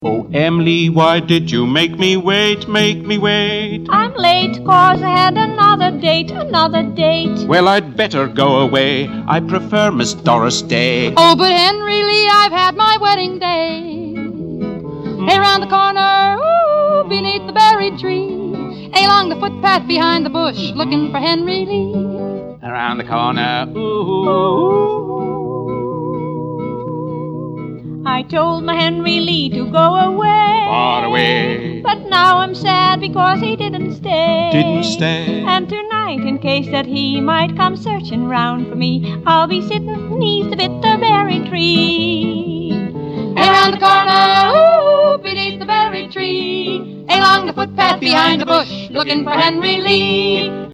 The set is beautifully produced, and sound quality is fine.